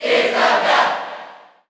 Crowd cheers (SSBU) You cannot overwrite this file.
Isabelle_Cheer_English_SSBU.ogg.mp3